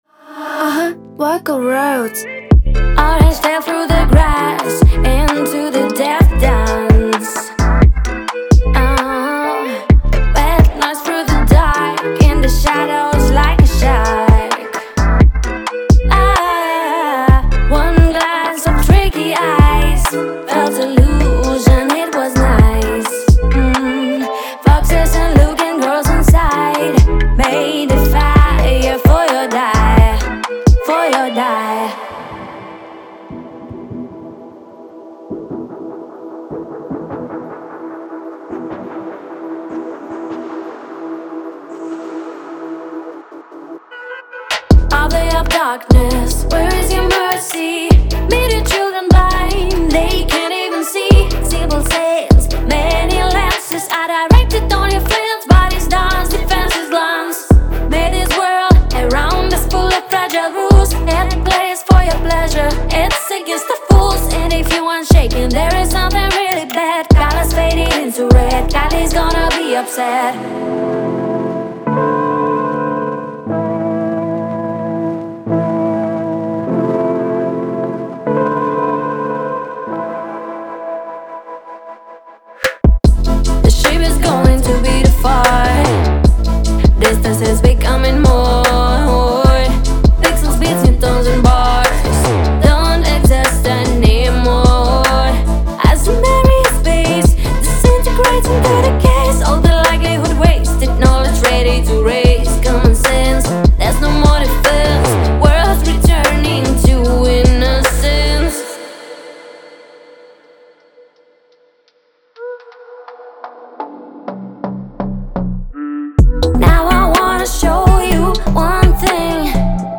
2025-12-30 Chill Trap · Future Pop 211 推广